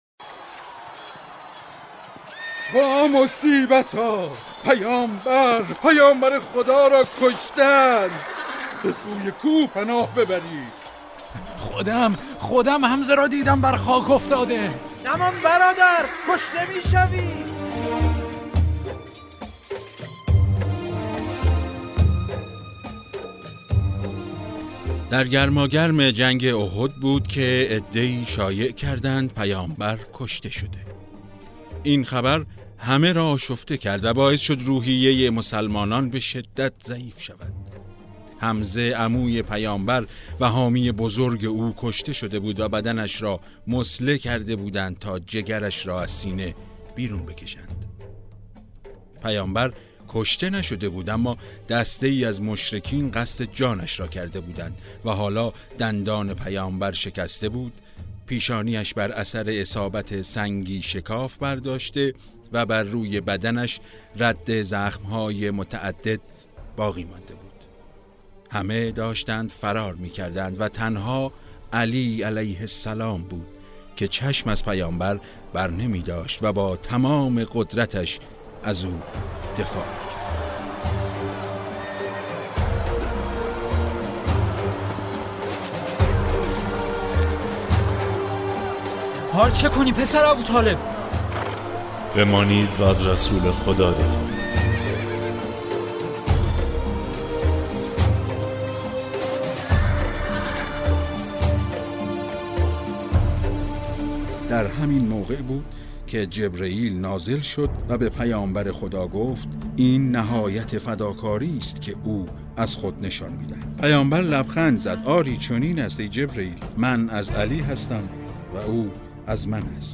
مجموعه ۸ روایت تا غدیر داستان‌های کوتاه و صوتی است که در دهه ولایت (عید قربان تا عید سعید غدیر) از شهرآرا نیوز منتشر شود.